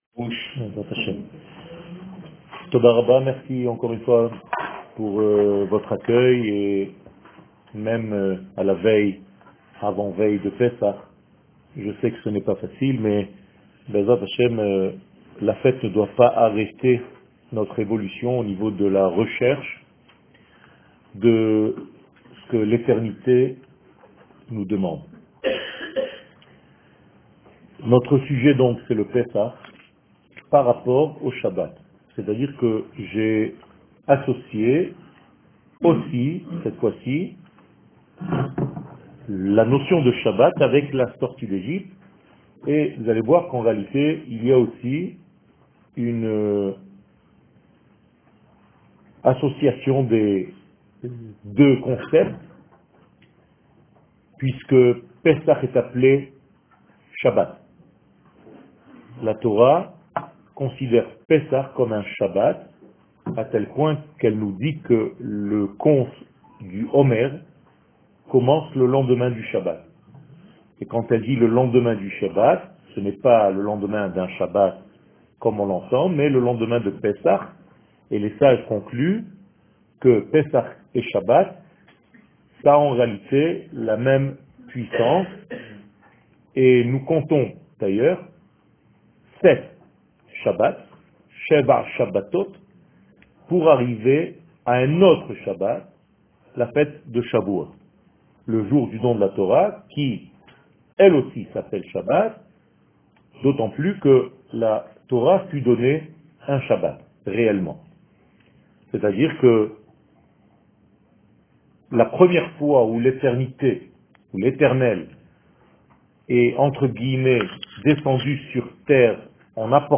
שיעורים, הרצאות, וידאו